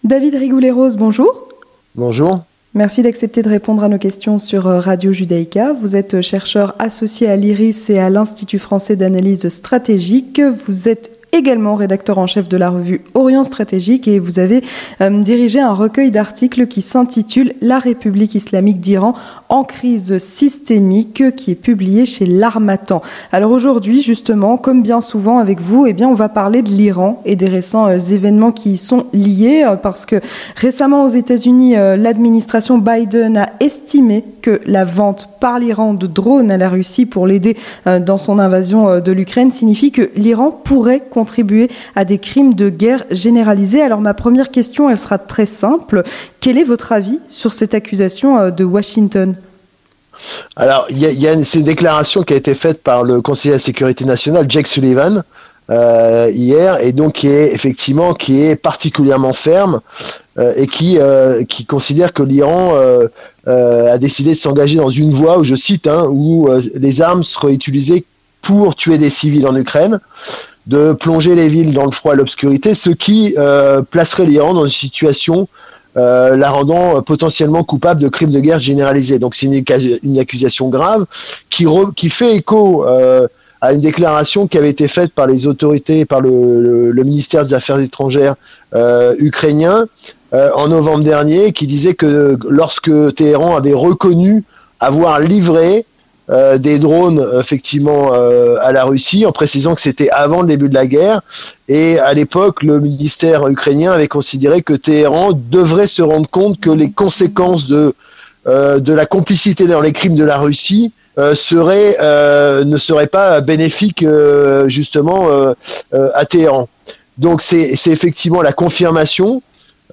3. L'entretien du 18h